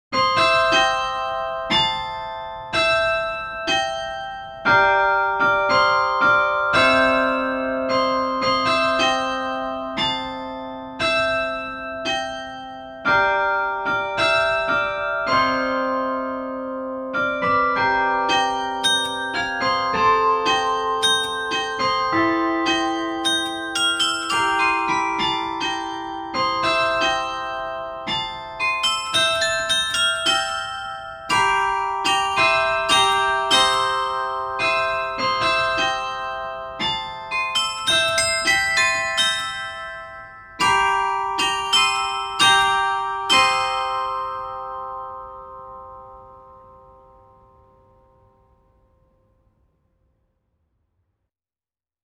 48 Glocken, vier Oktaven
Dabei geben die kleinen Glocken die helleren und hohen Töne wieder, die großen, schweren Glocken die tiefen Töne.